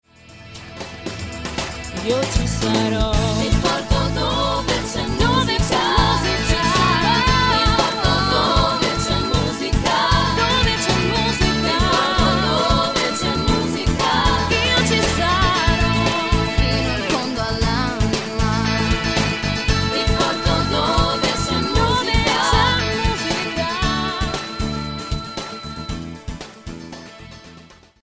Microfono registrazione voci AKG C 414 XLII